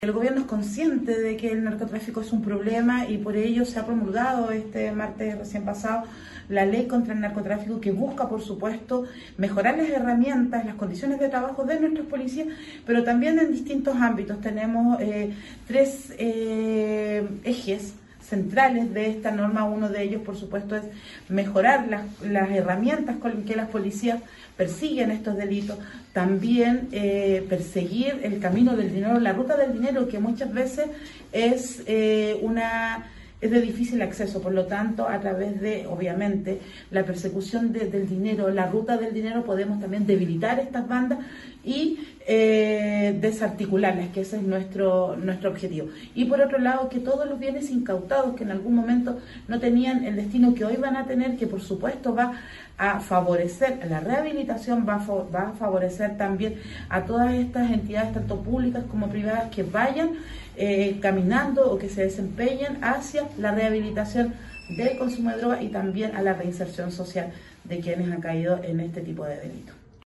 Mientras que la Delegada Presidencial provincial de Choapa, Nataly Carvajal señaló que